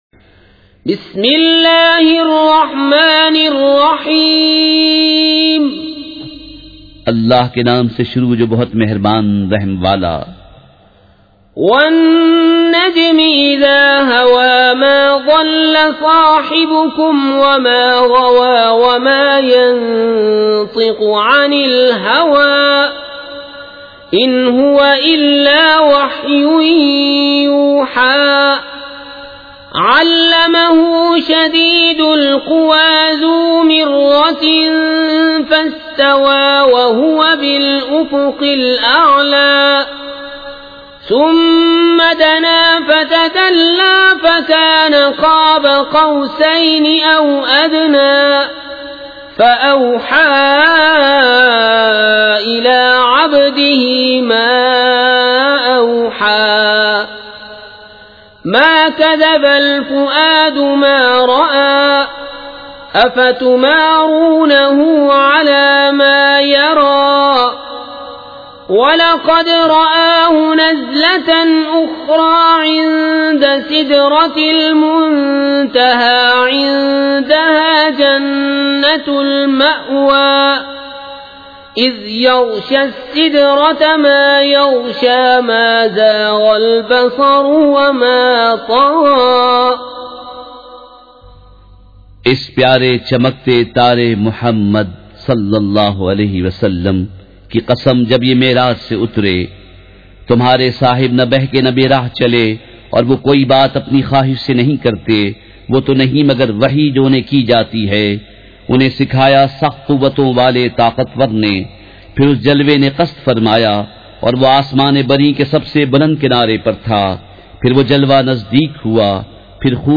سورۃ النجم مع ترجمہ کنزالایمان ZiaeTaiba Audio میڈیا کی معلومات نام سورۃ النجم مع ترجمہ کنزالایمان موضوع تلاوت آواز دیگر زبان عربی کل نتائج 3023 قسم آڈیو ڈاؤن لوڈ MP 3 ڈاؤن لوڈ MP 4 متعلقہ تجویزوآراء